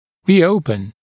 [ˌriː’əupən][ˌри:’оупэн]повторно открывать